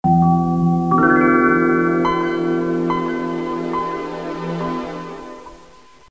NOISESTARTUP.mp3